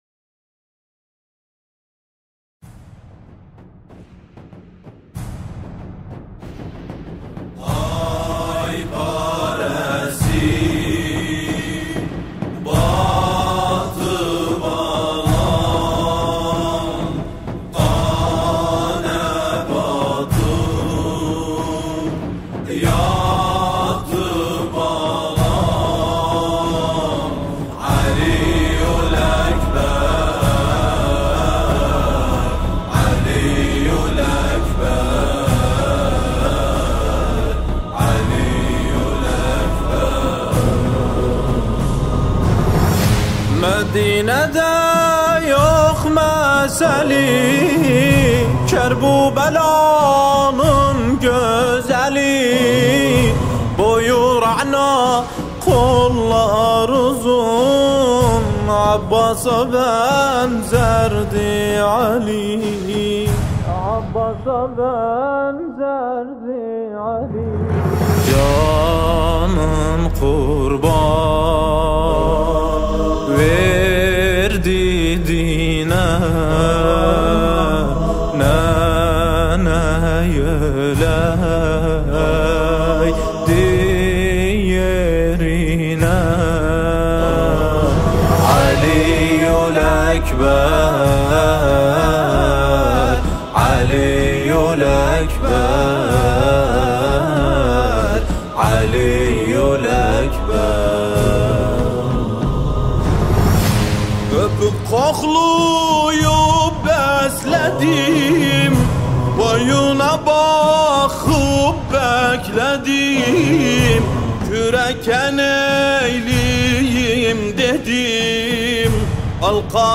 مداحی ترکی جدید
از کرکوک عراق